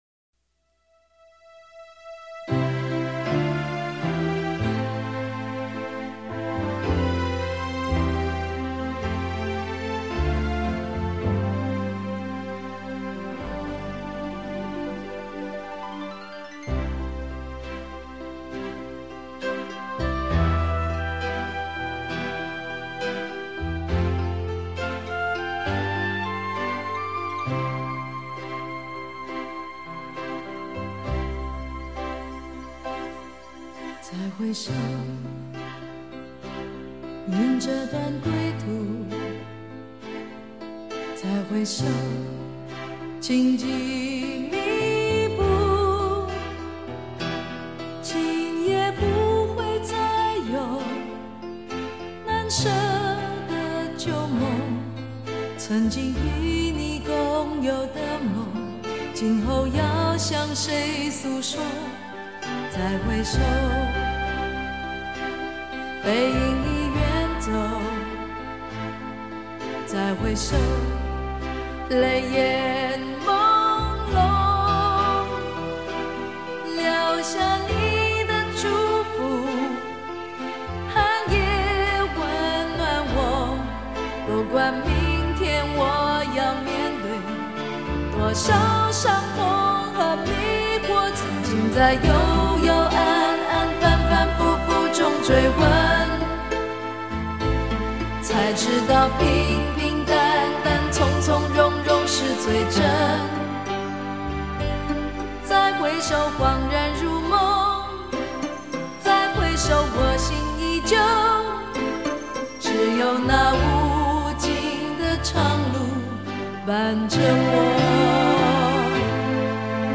她激昂的唱腔、感性的声音，不仅成为自身的标志，也在很大程度上引领了华语流行乐的走向。